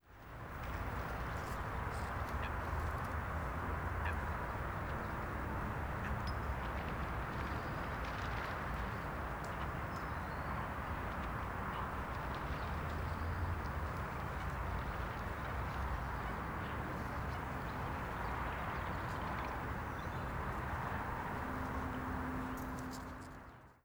Patos en una laguna de un parque